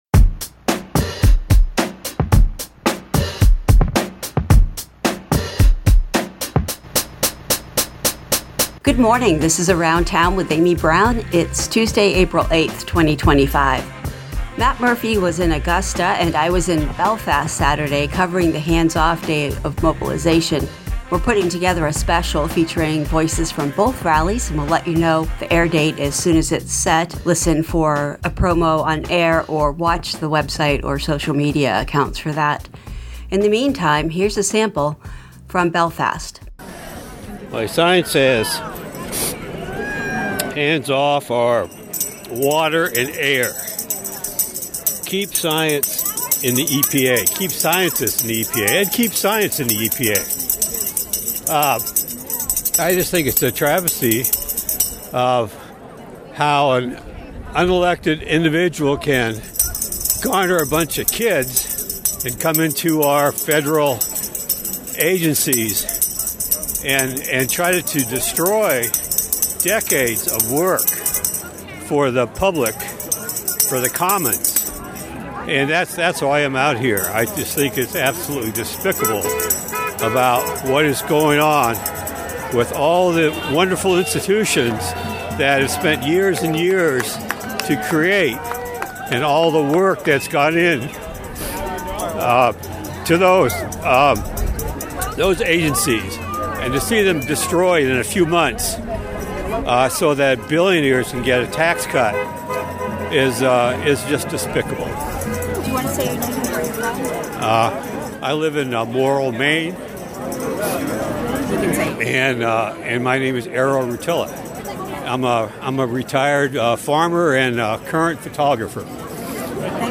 A little vox pop from the Belfast, Maine Hands Off 2025 event on Saturday